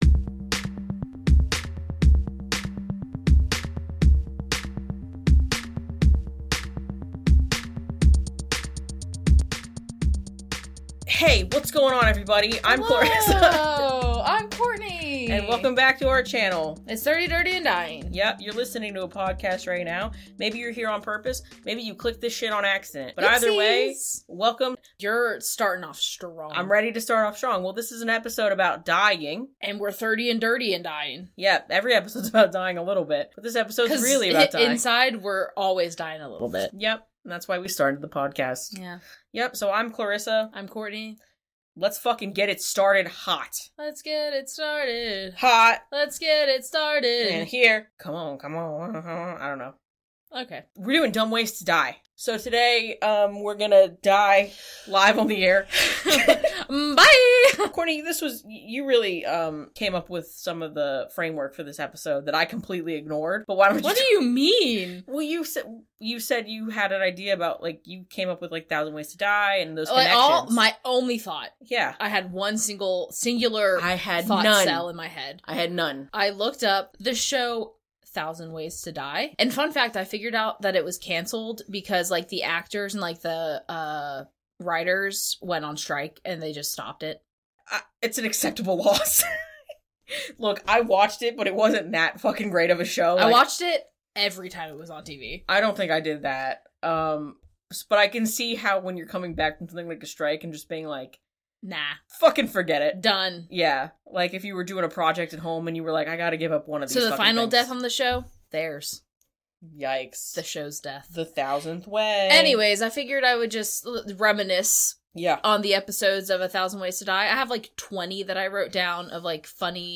There's some definite coping with dark humor in this one, and the content is by no means making fun of any one who had experiences like the ones discussed.
CW: Some discussion, though often comedic, about death, dying, and accidents throughout.